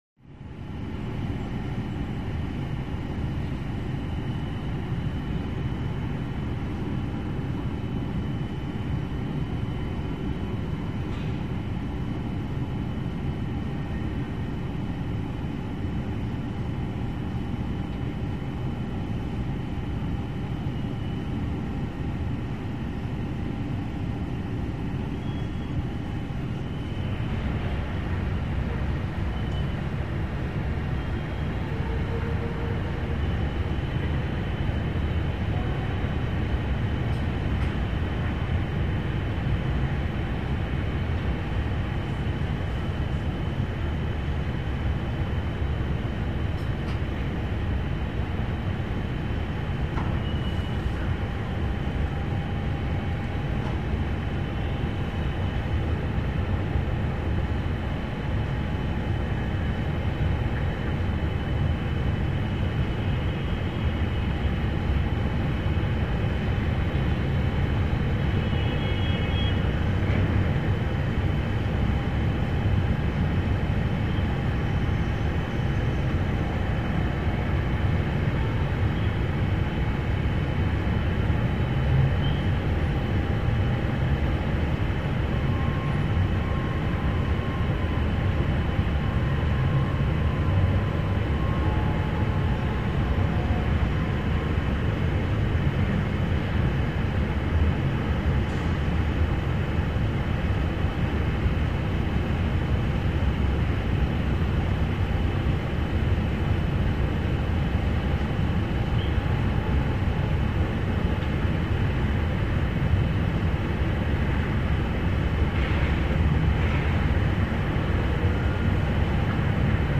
TrafficInteriorPOV CT051901
Traffic, Interior Point Of View, Closed Window. Very High Perspective. Heavy Traffic Drone Some Air Conditioner Tone From Interior Room.